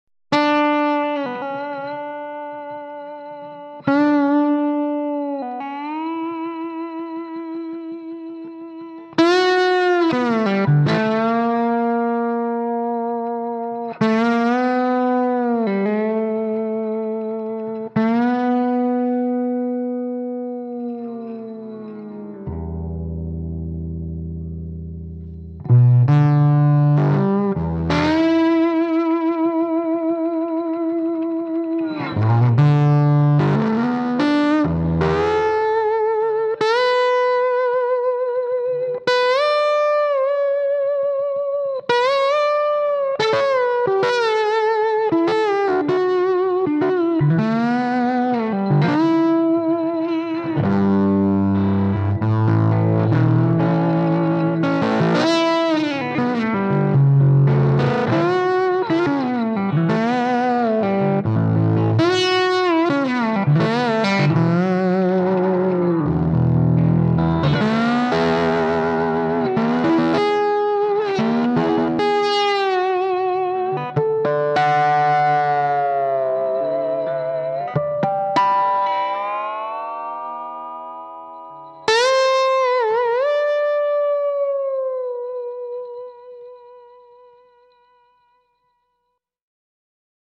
Les Paul Slide 1   1:30